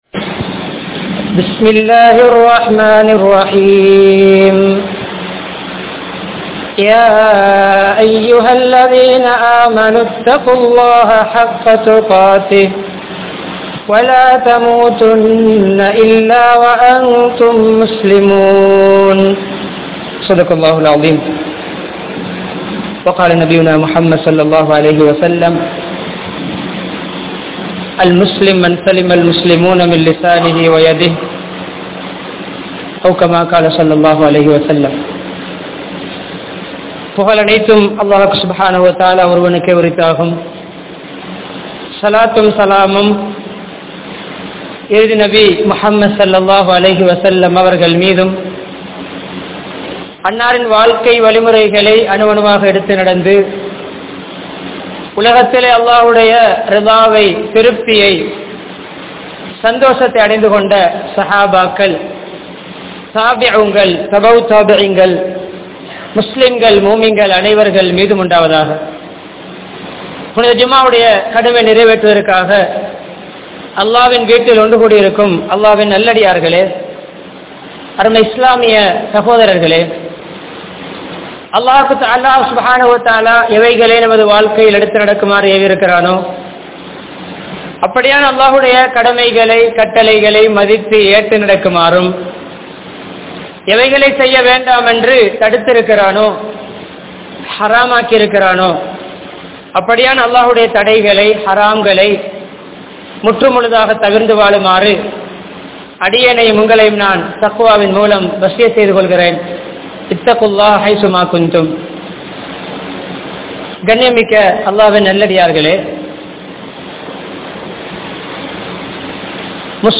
Muslimin Panpuhal (முஸ்லிமின் பண்புகள்) | Audio Bayans | All Ceylon Muslim Youth Community | Addalaichenai